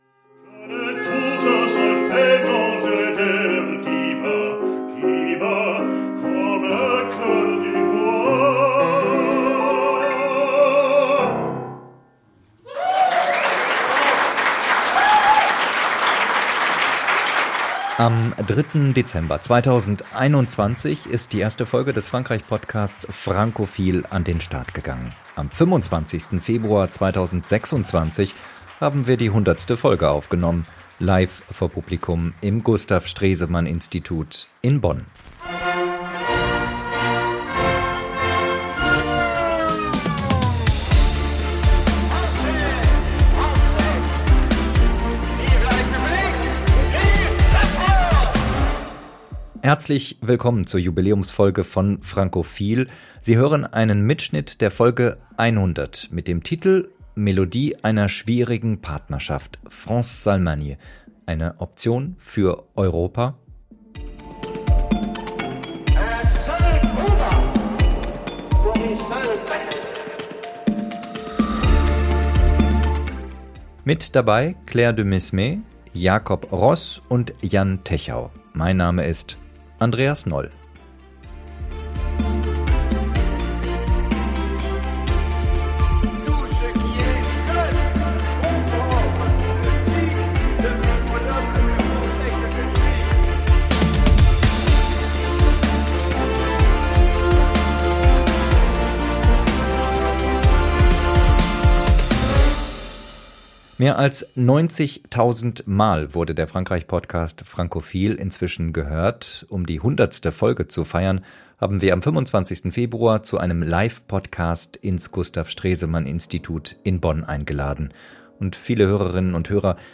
Seine Musik war an diesem Abend mehr als Begleitung: Sie bildete den emotionalen Resonanzraum der Debatte. Im Podcast selbst sind aus urheberrechtlichen Gründen nur kurze Ausschnitte zu hören.